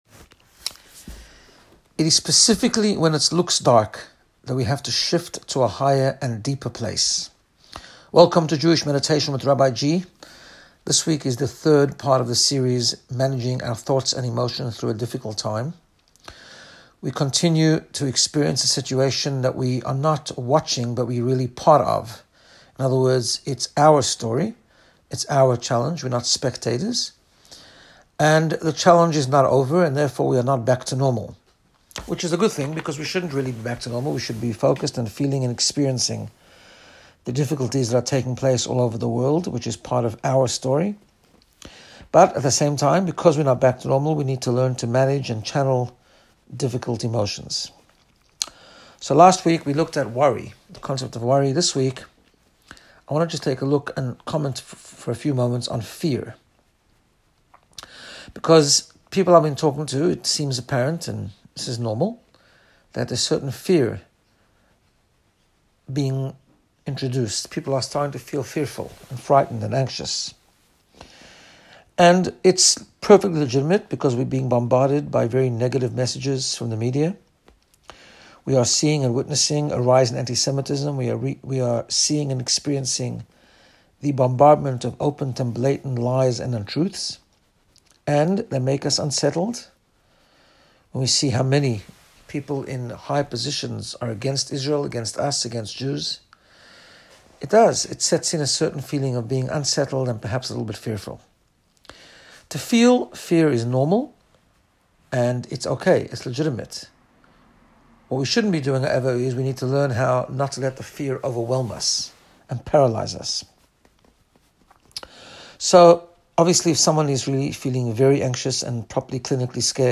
Meditation-vayeira.m4a